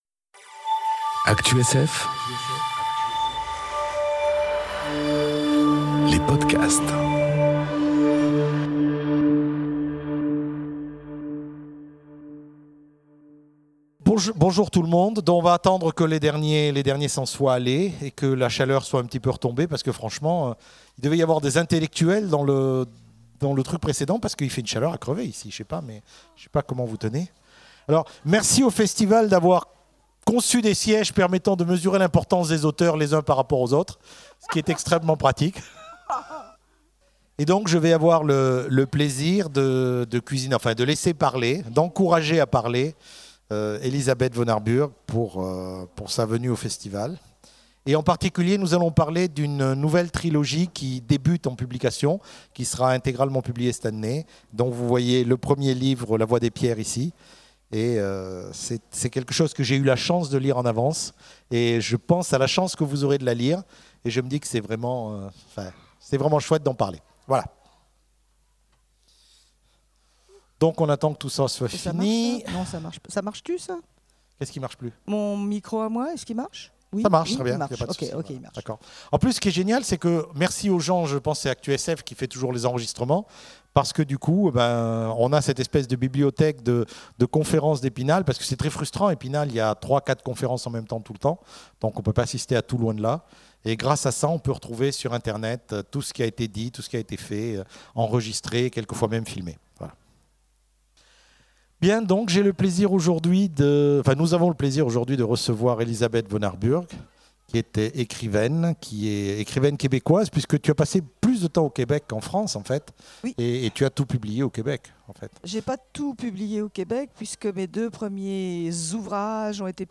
Imaginales 2018 : Entretien avec Élisabeth Vonarburg
- le 15/06/2018 Partager Commenter Imaginales 2018 : Entretien avec Élisabeth Vonarburg Télécharger le MP3 à lire aussi Elisabeth Vonarburg Genres / Mots-clés Rencontre avec un auteur Conférence Partager cet article